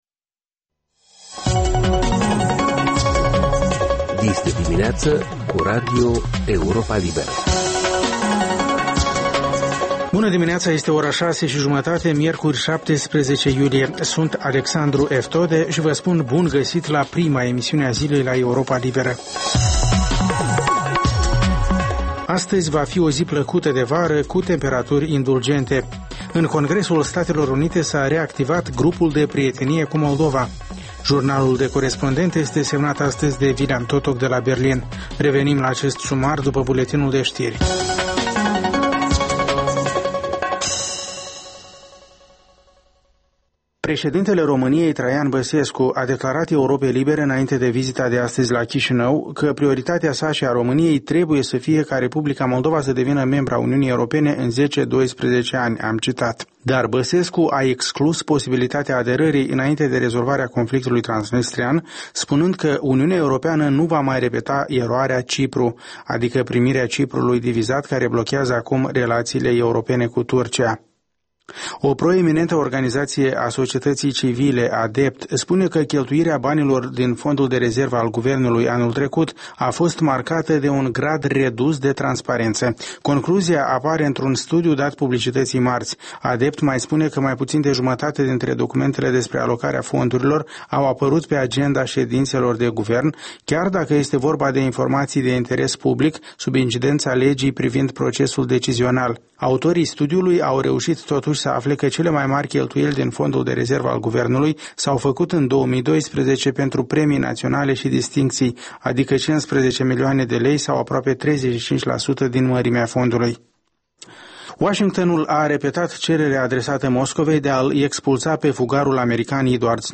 Ştiri, informaţii, interviuri, corespondenţe.